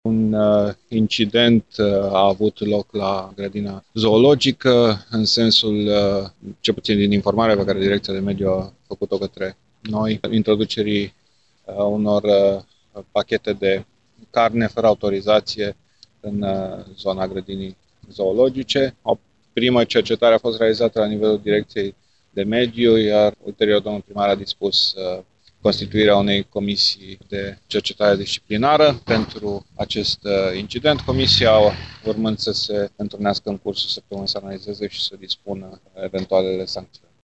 Viceprimarul Dan Diaconu spune că Direcţia de Mediu a sesizat conducerea municipalităţii, deoarece nu ar fi fost respectate procedurile legale pentru această donație.